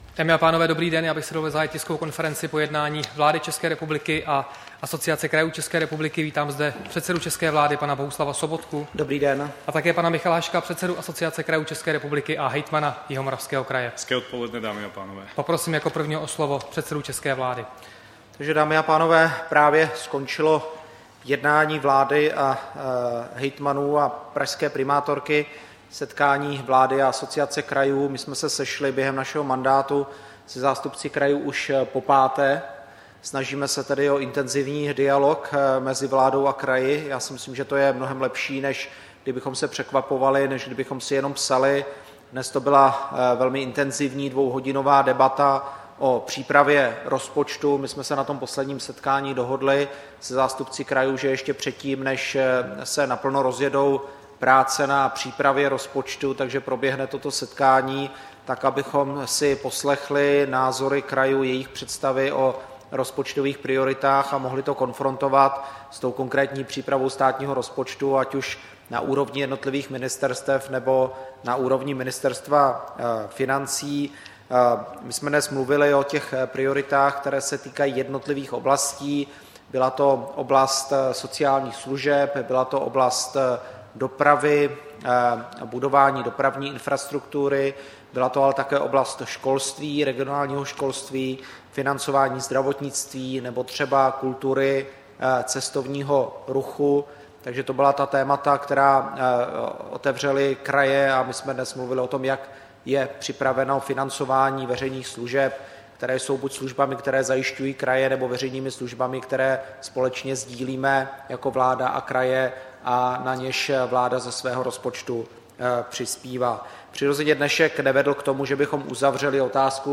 Tisková konference po jednání vlády s Asociací krajů ČR, 1. července 2015